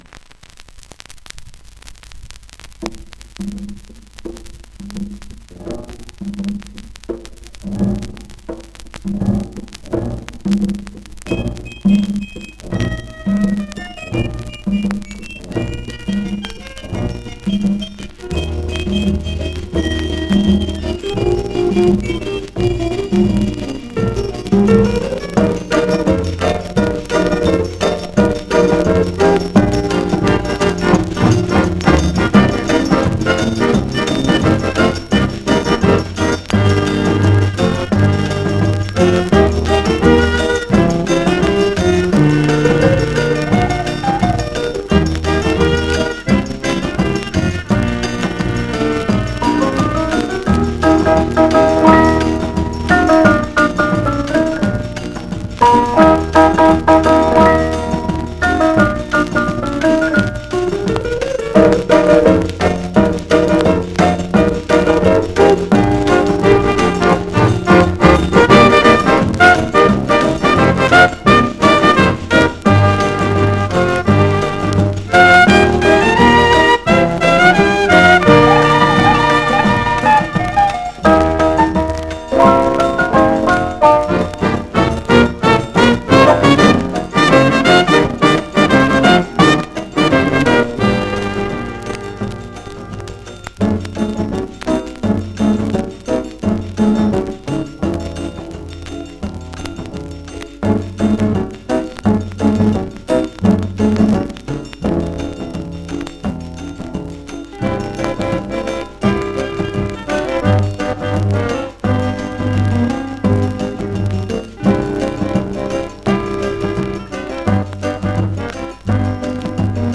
1 disco : 78 rpm ; 25 cm Intérprete
con su acordeón y su banda